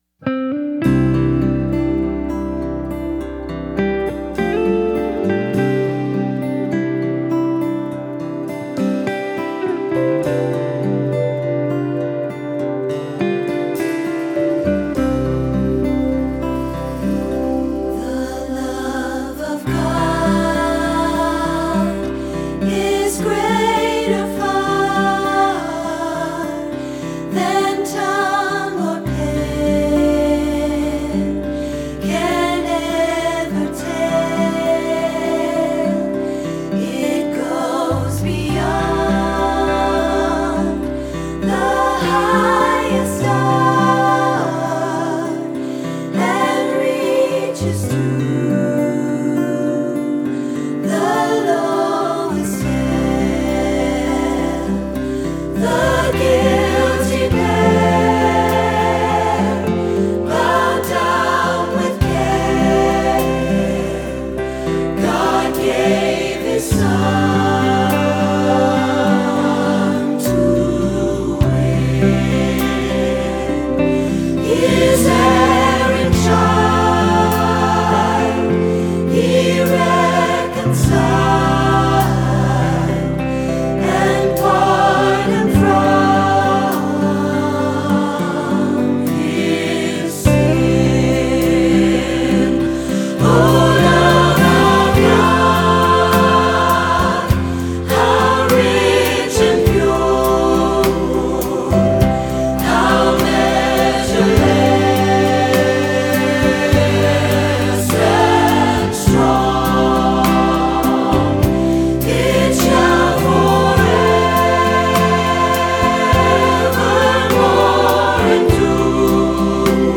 Choir Music to practice with